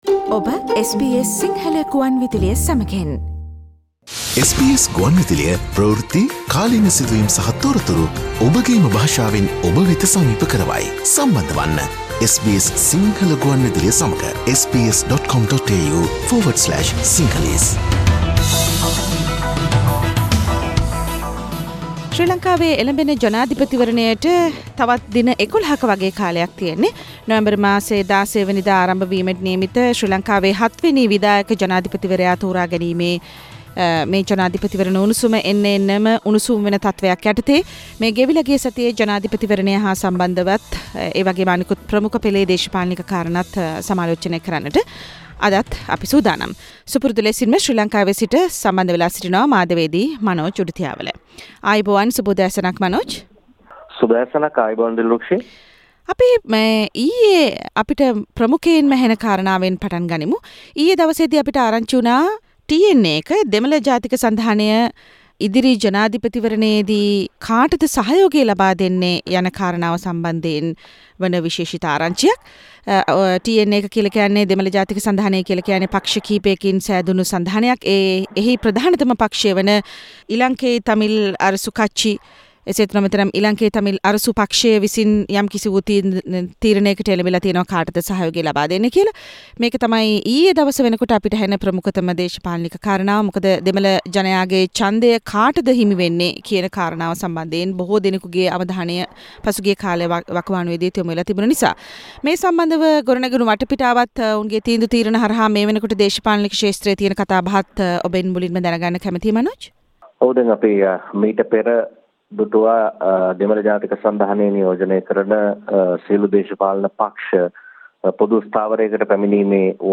ශ්‍රී ලාංකීය දේශපාලනයෙන් ඇසෙන උණුසුම් කතා : සතියේ දේශපාලන පුවත් සමාලෝචනය